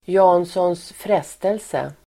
Ladda ner uttalet
Janssons frestelse pronomen, Jansson's temptation Uttal: [ja:nsåns ²fr'es:telse] Förklaring: Gratinerad maträtt på bl a potatis, ansjovis och lök.